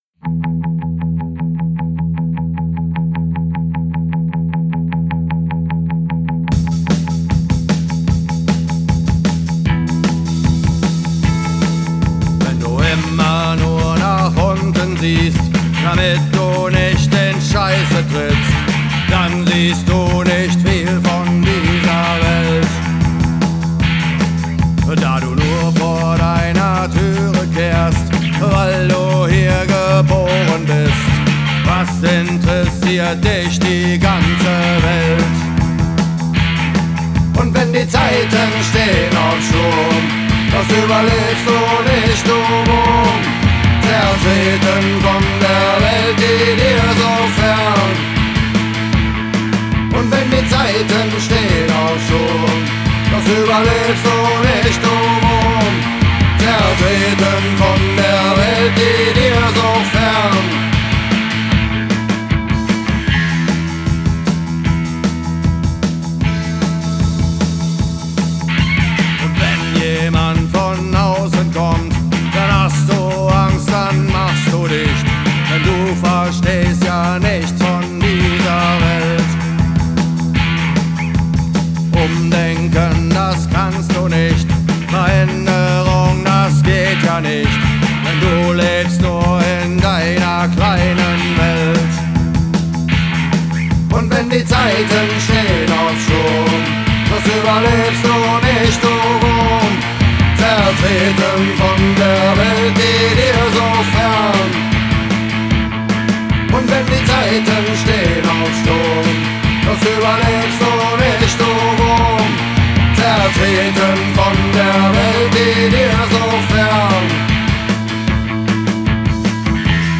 zwei neue Songs frisch aus dem Proberaum.